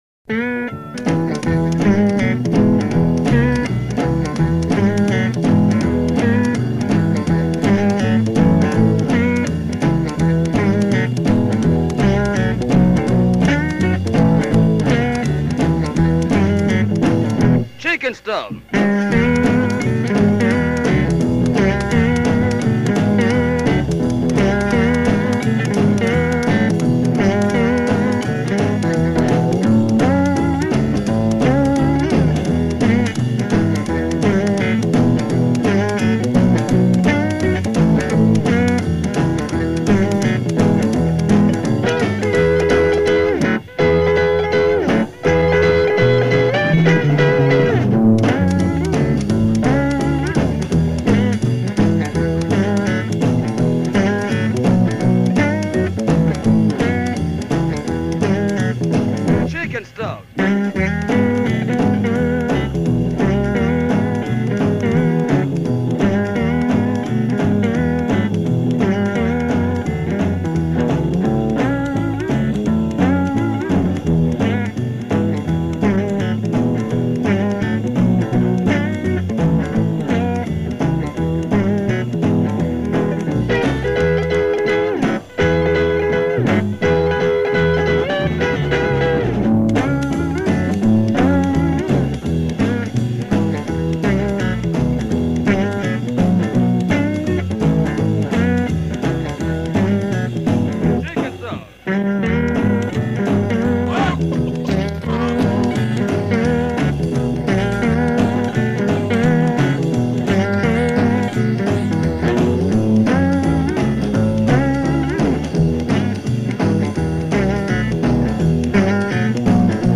steel slide style